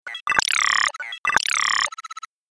جلوه های صوتی
دانلود صدای ربات 34 از ساعد نیوز با لینک مستقیم و کیفیت بالا